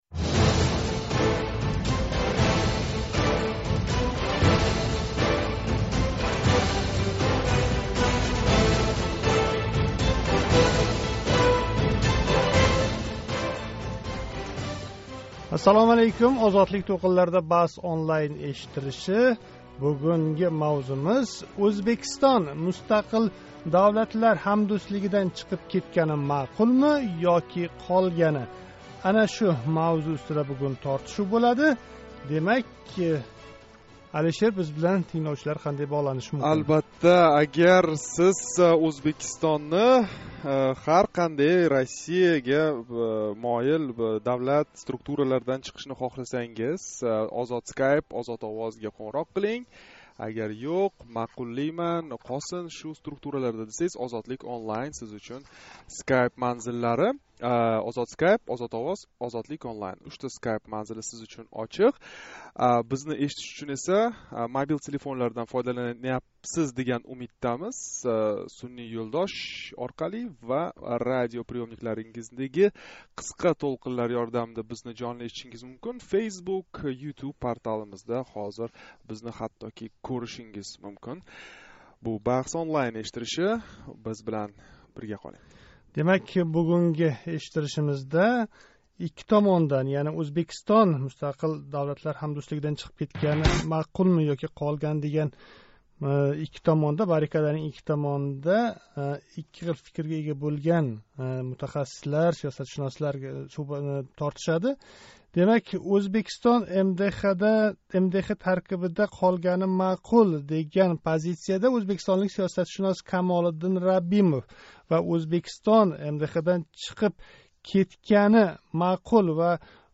BahsOnlineнинг навбатдаги сонида Ўзбекистоннинг МДҲ ва шу каби Кремль раҳбарлигидаги бошқа ташкилотларда қолгани маъқулми ёки Украина сингари тарк этгани маъқулми, деган савол устида баҳс бўлди.